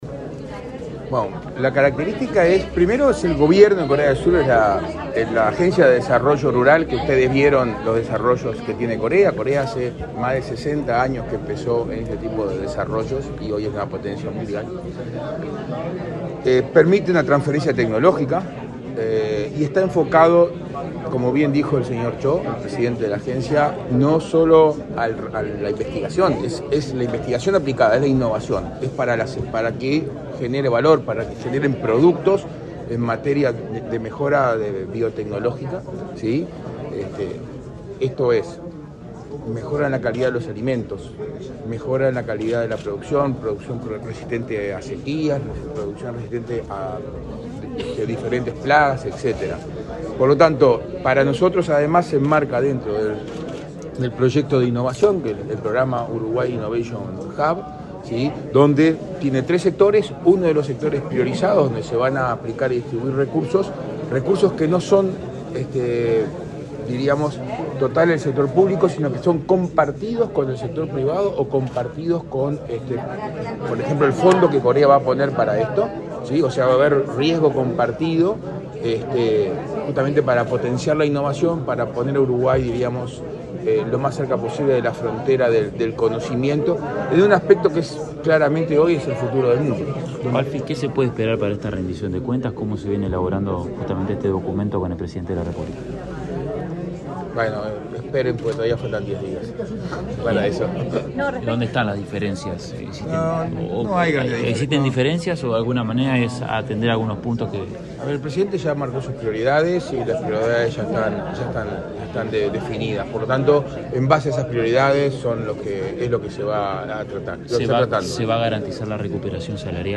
Declaraciones del director de OPP, Isaac Alfie
Representantes del Ministerio de Ganadería, Agricultura y Pesca, la Oficina de Planeamiento (OPP) y Presupuesto y la Administración de Desarrollo Rural de la República de Corea firmaron, este jueves 22, en la Torre Ejecutiva, un memorando de entendimiento para cooperar en biotecnología agrícola. Luego, el director de la OPP, Isaac Alfie, dialogó con la prensa.